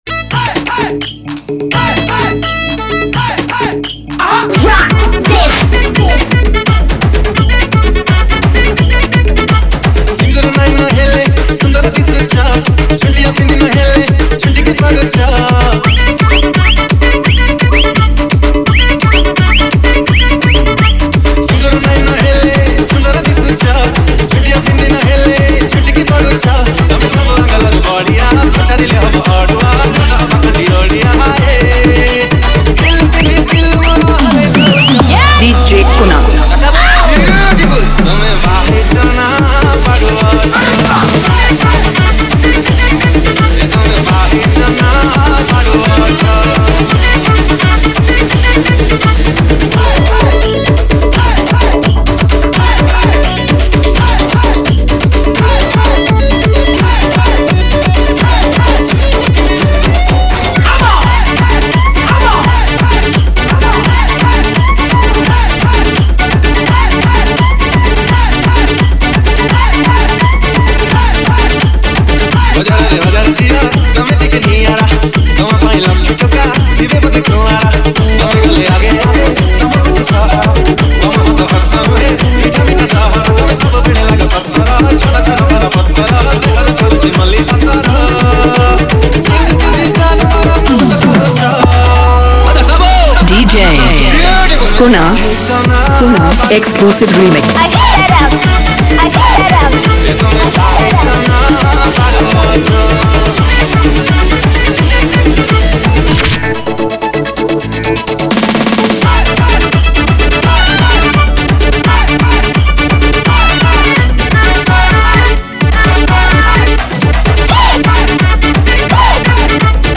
• Category:Odia New Dj Song 2017